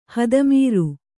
♪ hada mīru